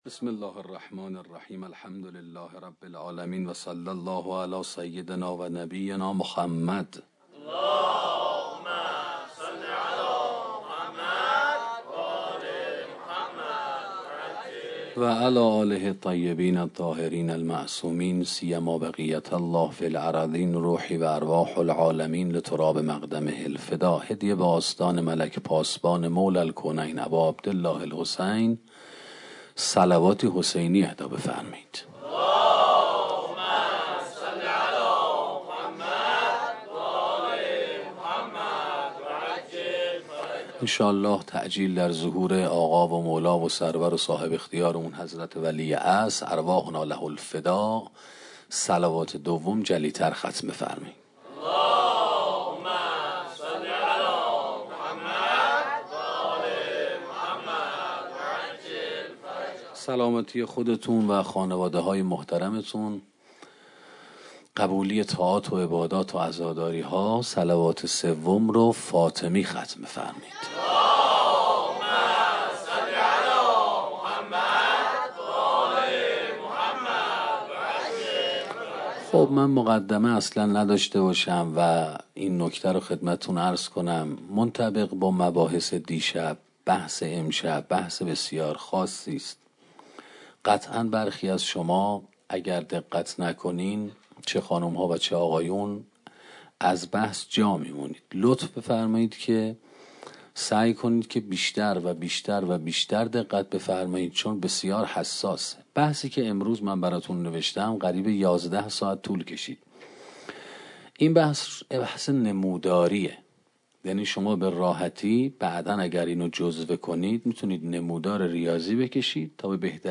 سخنرانی مجاهدت و نفس 2